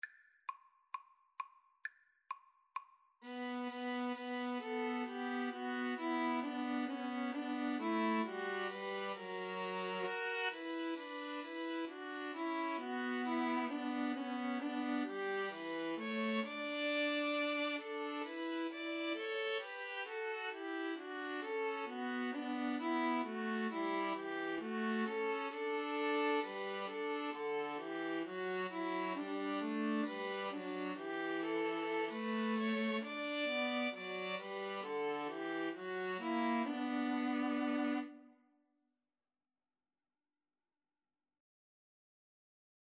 Violin 1Violin 2Viola
The melody is in the minor mode.
4/4 (View more 4/4 Music)